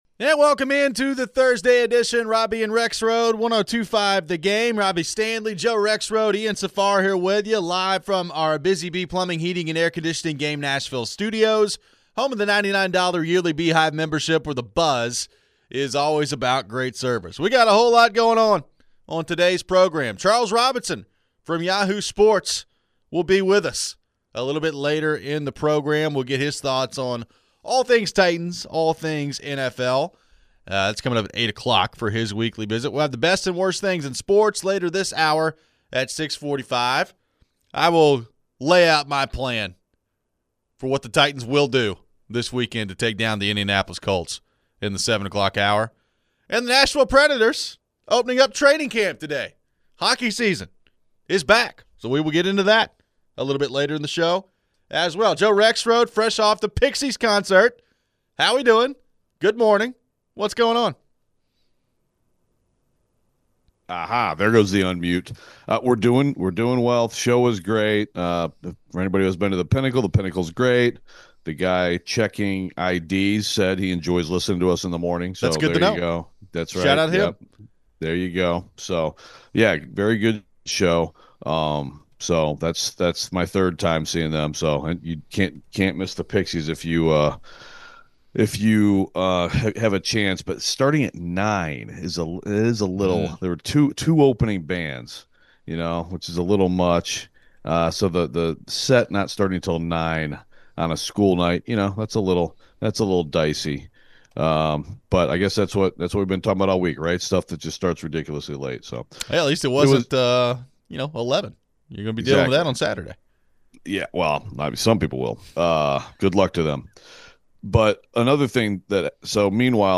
What do we make of the TNF matchup tonight? A lot of fans are out on Brian Callahan already, but do we see an obvious top NFL candidate this season? We head to your phones.